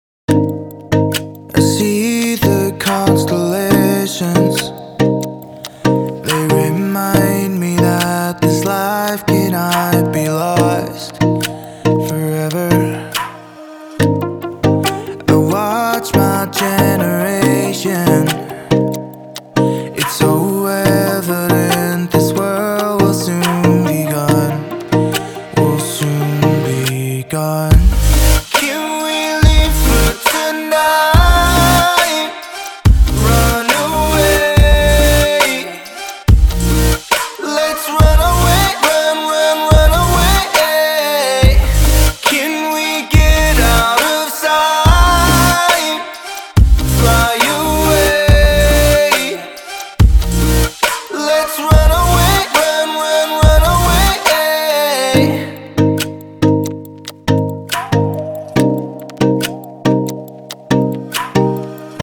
包含5条完整的无伴奏曲音轨，由两位专业歌手录制和编辑，一男一女。
除了这些高质量的无伴奏合唱之外，我们还包括75个旋律ad-lib和20个很棒的人声回路。